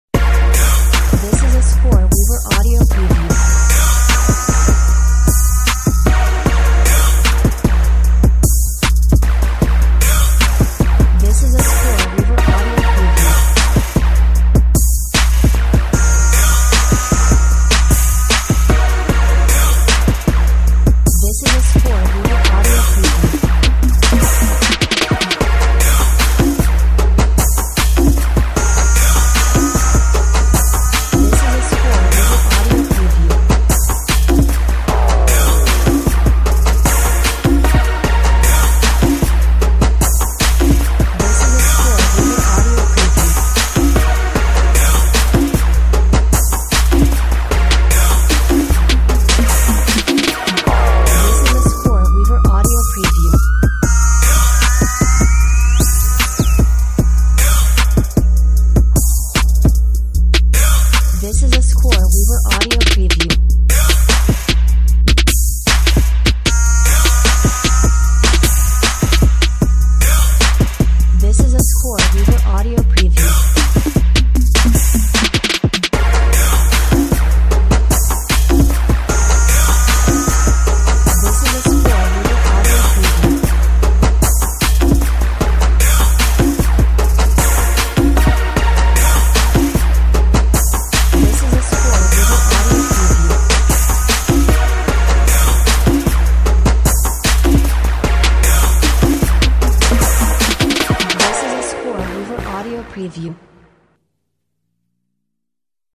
Sizzling hot Hip Hop / Trap Step with a buzzing synth line!